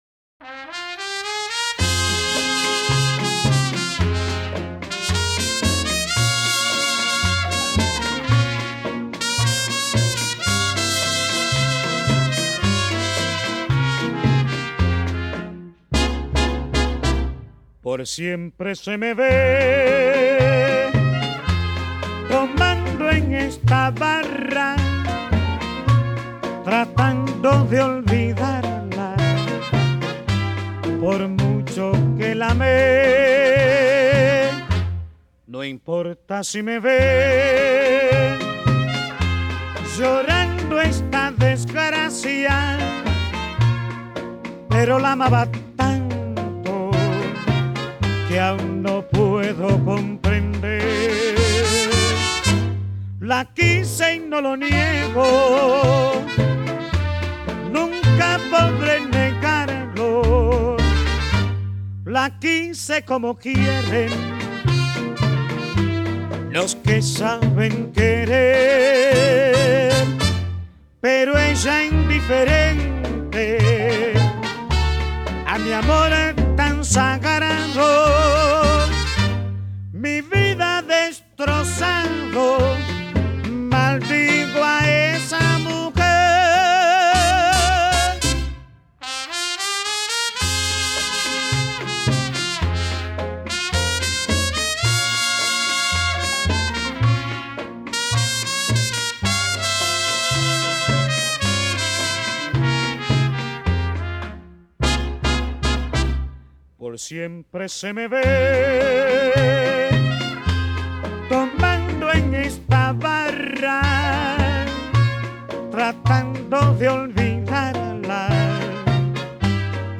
Bolero